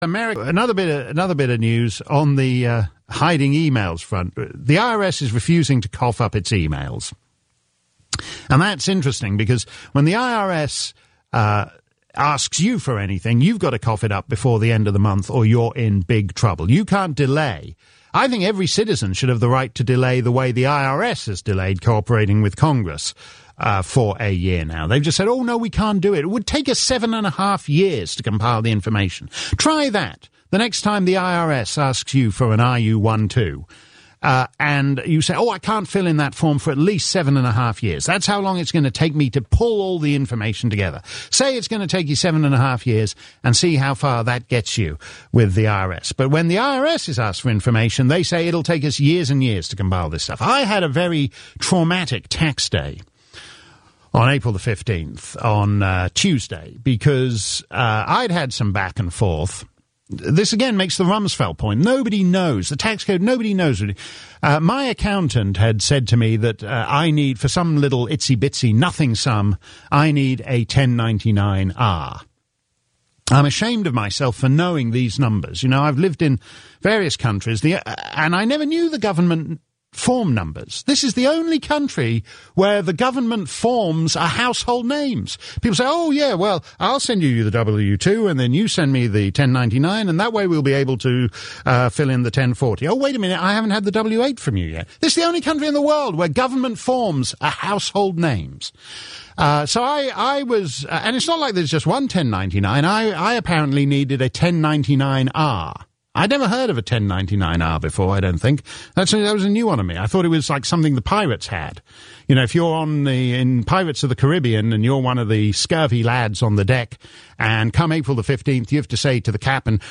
I always enjoy Mark Steyn’s observations about life in the USA when he subs for Rush Limbaugh on his radio show.  Maybe it’s the irony of hearing someone with a posh British accent comment on American issues that makes it so humorous, but Mark’s rants about the insanity of our income tax system really do crack me up.